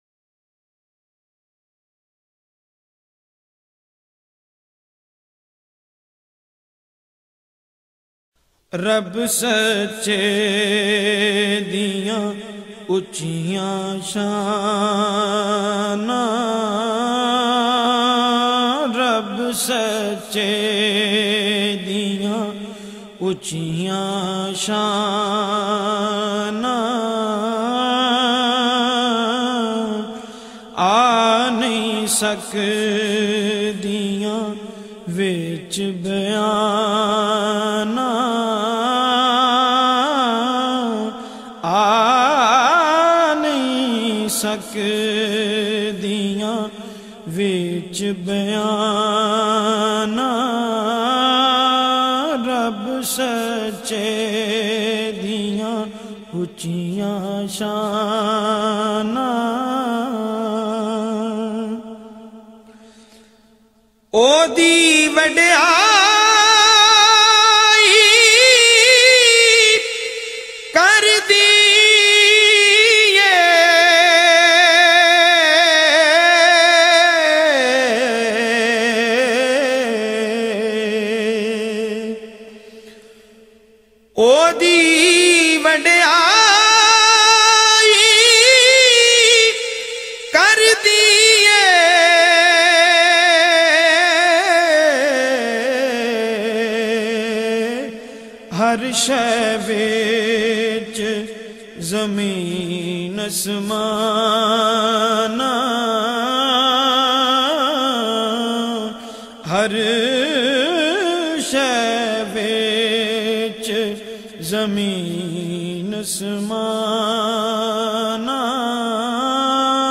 very sweet and magical voice with wonderful control